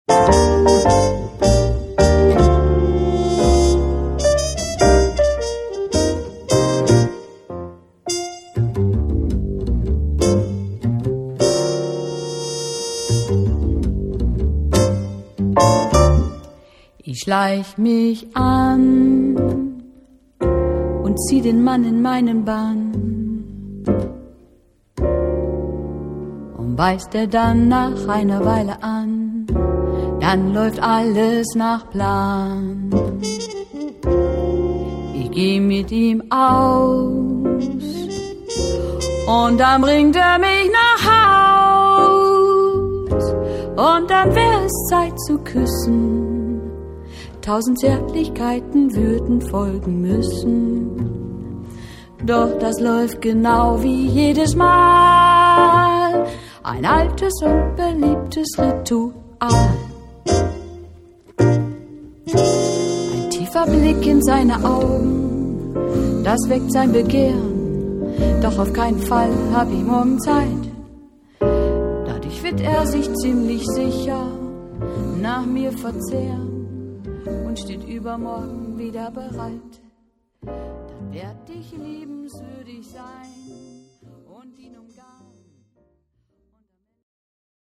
Gesang
Klavier
Kontrabass
Trompete